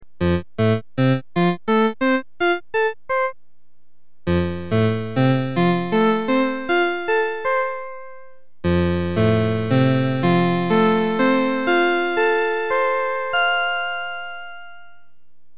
Sounds (These were all done on my Lowrey T2)
Sustain:  All voices, Brilliance Full, Vibrato On, Slow, Light, arpeggiate three chords three times:
Sustain Off
Sustain Med
Sustain Long